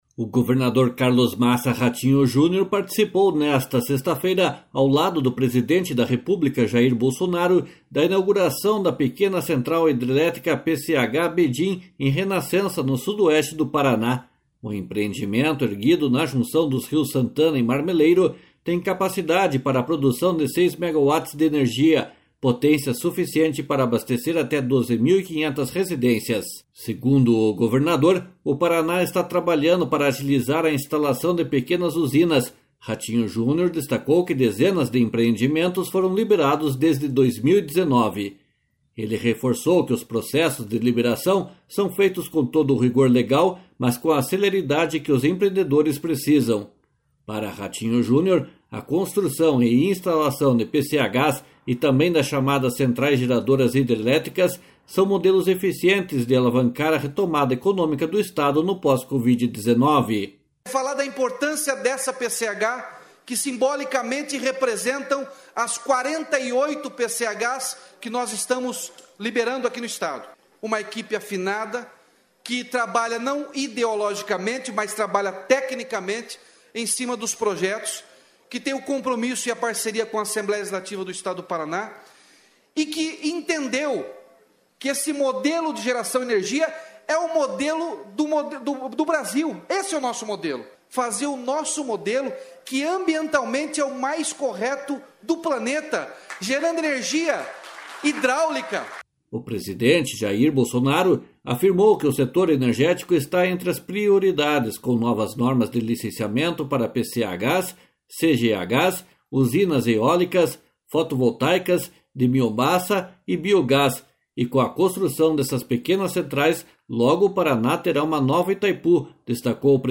//SONORA RATINHO JUNIOR//
//SONORA JAIR BOLSONARO//
//SONORA GUTO SILVA//